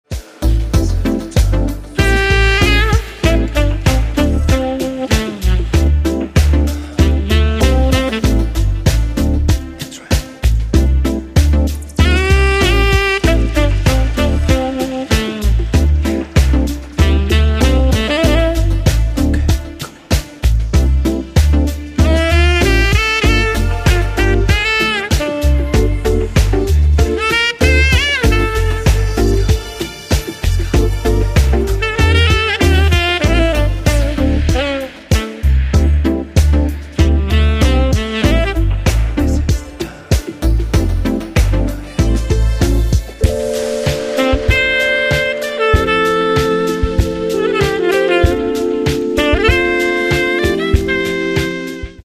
Saxophon & coole Sounds
Tenor-Saxophon